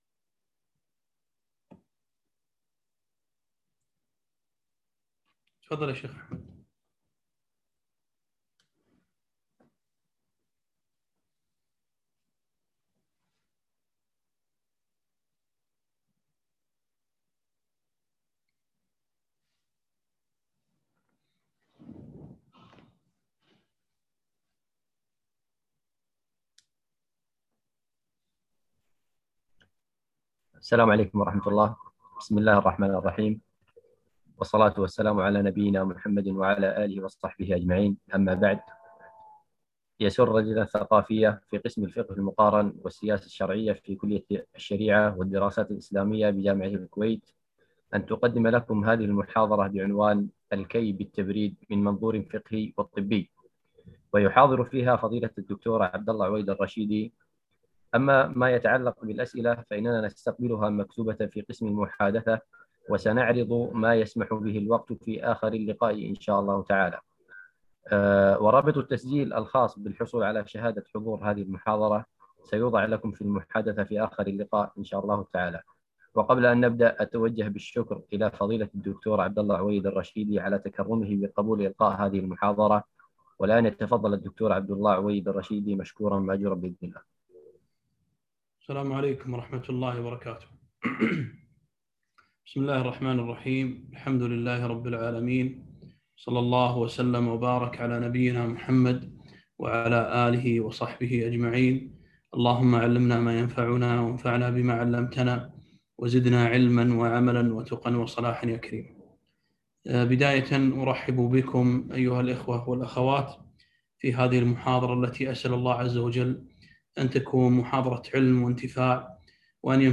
محاضرة الكي بالتبريد من منظور فقهي وطبي